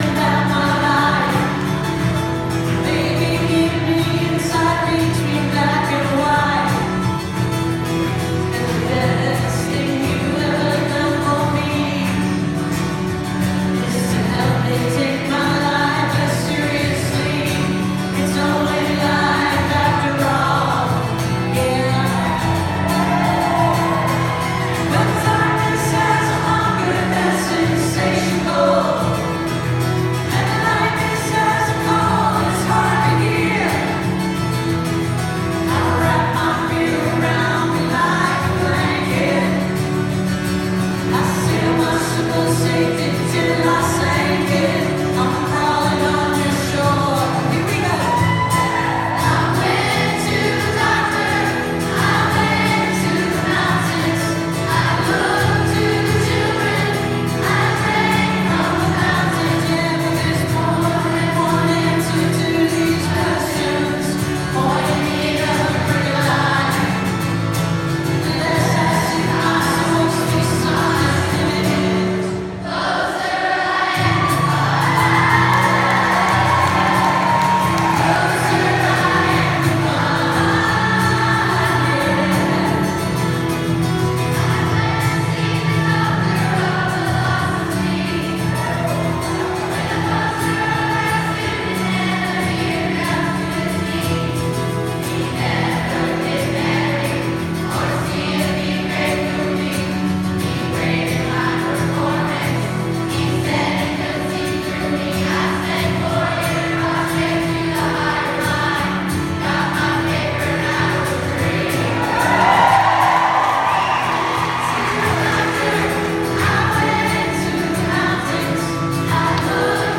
(captured from the youtube videos)